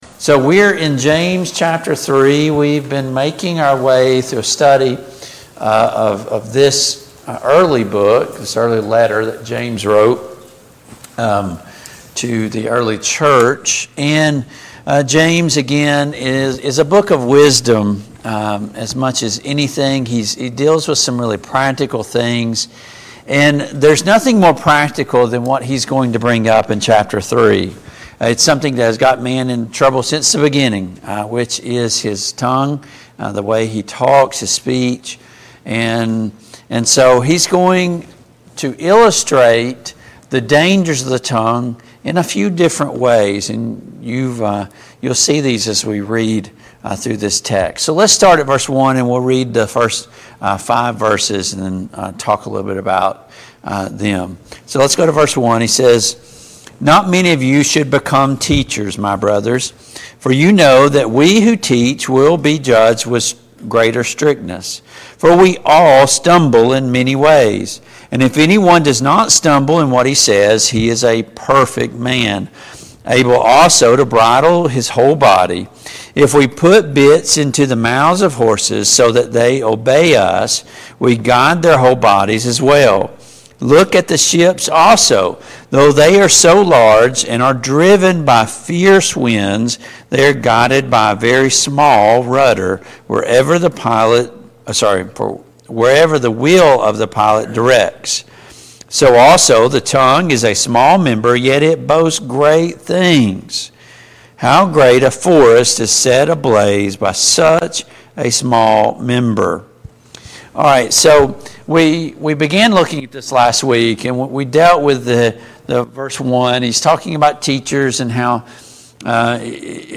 James 3:1-8 Service Type: Family Bible Hour Topics: Our Speech « Are you mindful of the things of God?